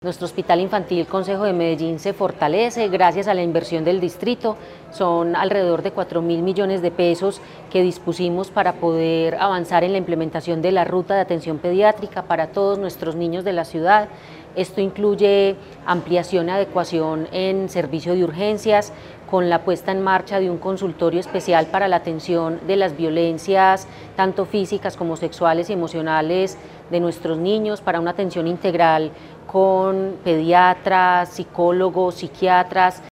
Declaraciones de la secretaria de Salud, Natalia López Delgado La Administración Distrital sigue mejorando la atención en salud para la niñez con la implementación de nuevos servicios en el Hospital Infantil Concejo de Medellín.
Declaraciones-de-la-secretaria-de-Salud-Natalia-Lopez-Delgado.mp3